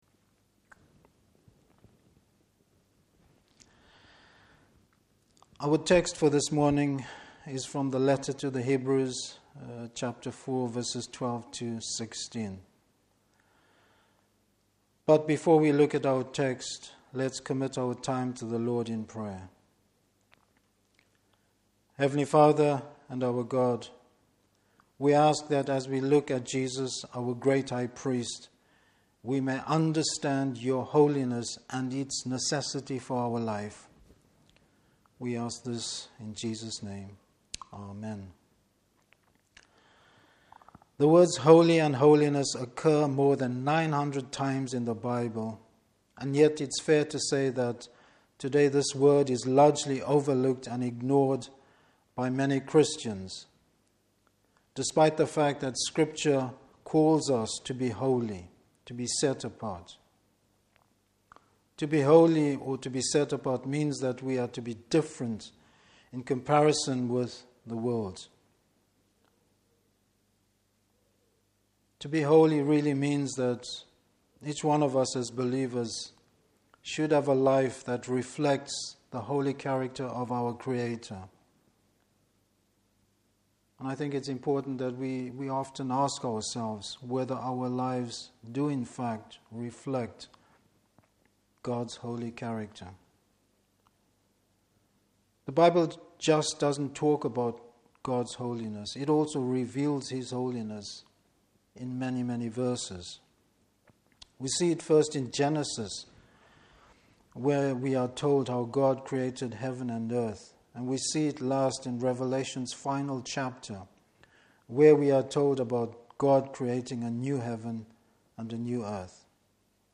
Service Type: Morning Service Jesus, our great high priest.